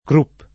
[ krup ]